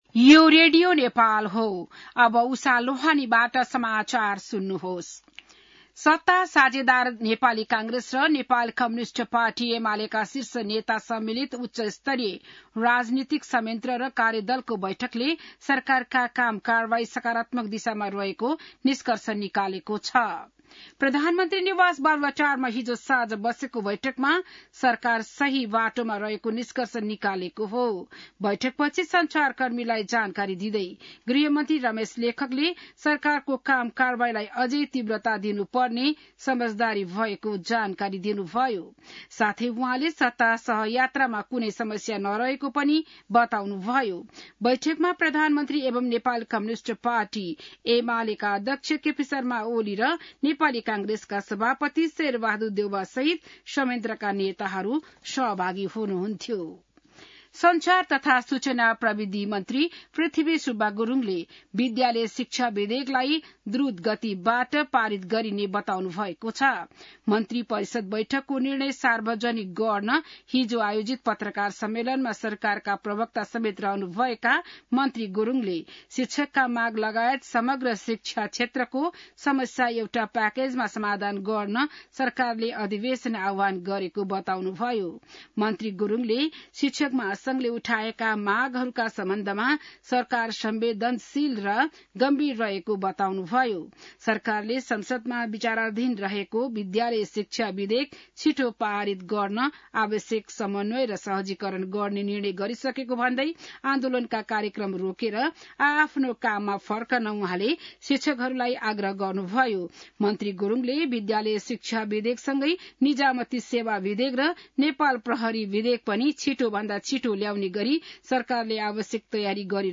बिहान १० बजेको नेपाली समाचार : ४ वैशाख , २०८२